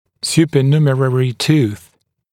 [ˌs(j)uːpə’njuːmərərɪ tuːθ] [ˌс(й)у:пэ’нйу:мэрэри ту:с] сверхкомплектный зуб